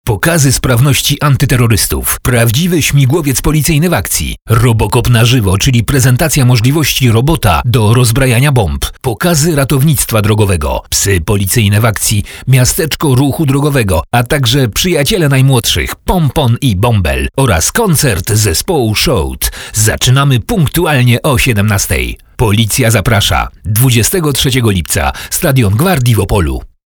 Professioneller polnischer Sprecher für TV / Rundfunk / Industrie.
Kein Dialekt
Sprechprobe: Werbung (Muttersprache):
polish voice over artist